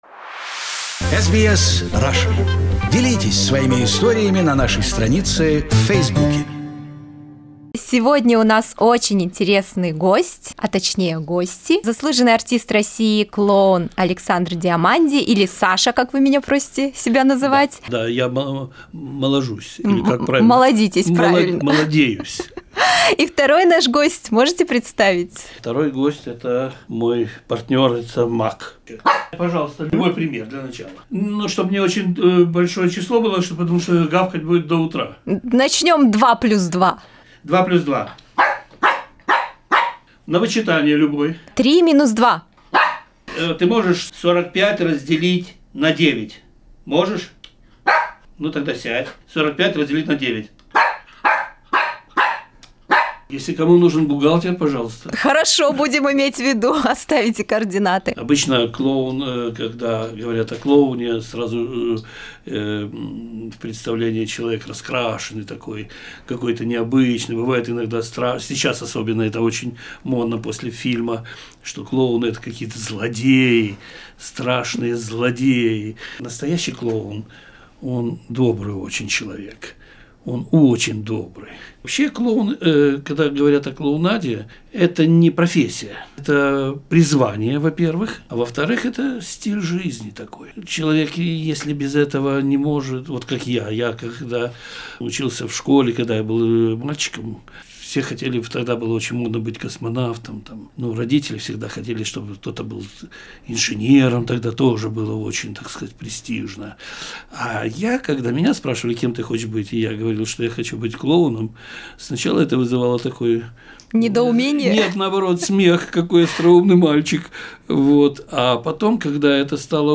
A circus performance took place at SBS Russian studio for the joy of the producers and operators.